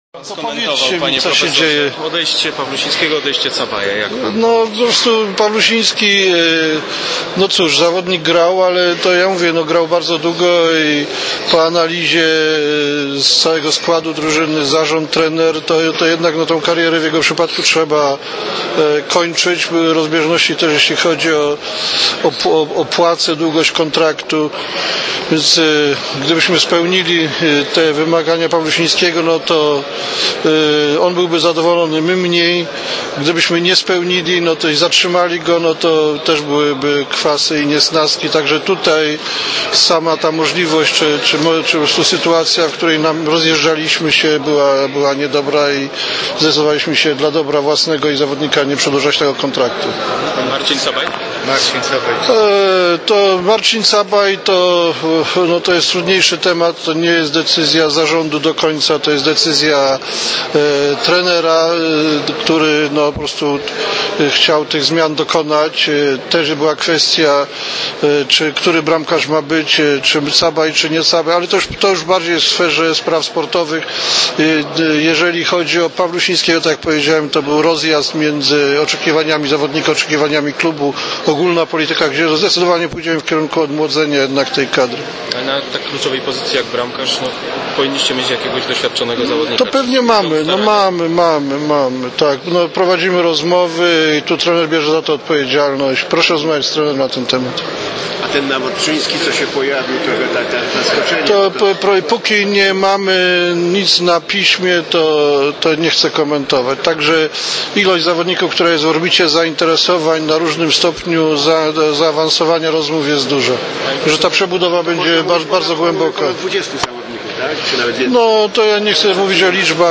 We wtorek w siedzibie Comarchu odbyło się tradycyjne spotkanie opłatkowe.
Przedstawiamy dźwiękowy zapis tej rozmowy.